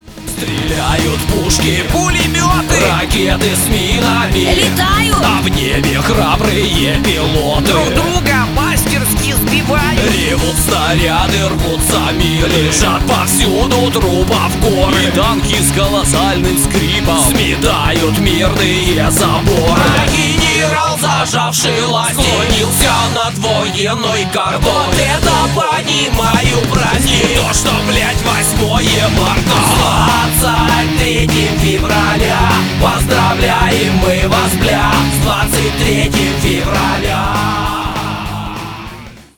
Внимание Ненормативная лексика!
Рок Металл
громкие